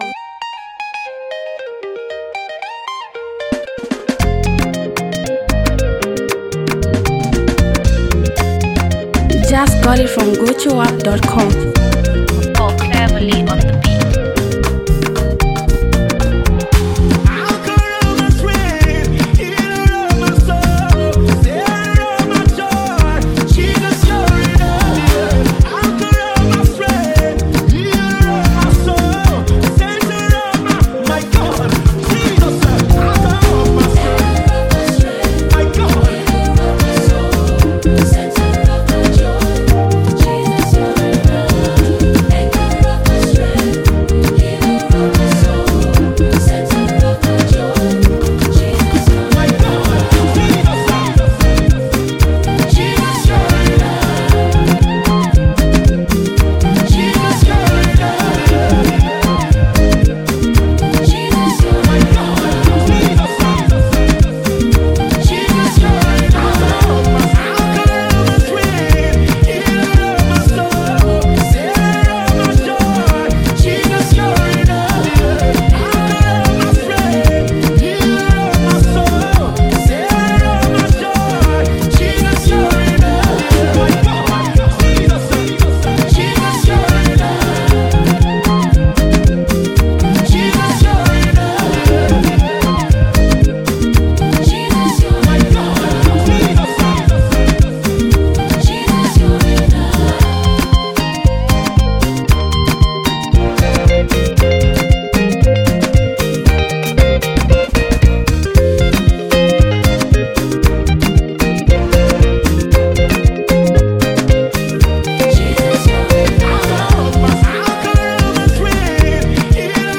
powerful melodic sound record